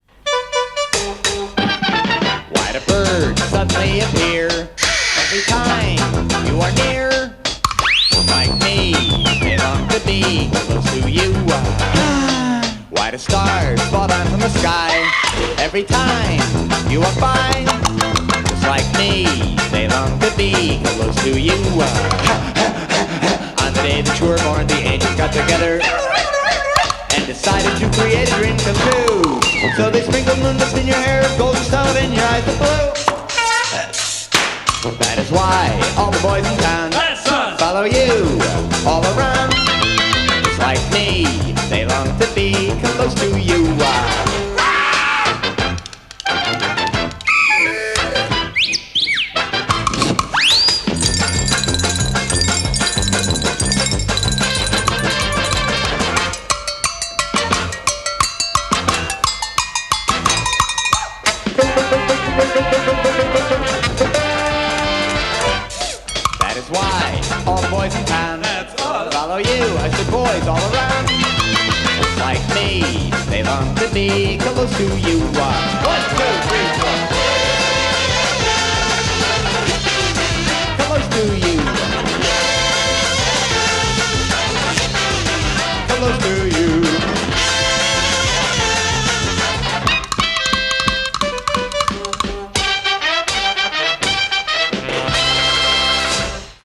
BPM165-210
Audio QualityCut From Video